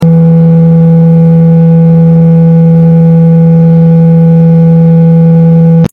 cooling sound